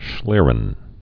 (shlîrən)